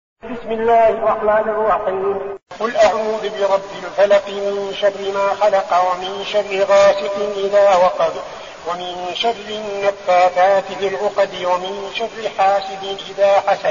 المكان: المسجد النبوي الشيخ: فضيلة الشيخ عبدالعزيز بن صالح فضيلة الشيخ عبدالعزيز بن صالح الفلق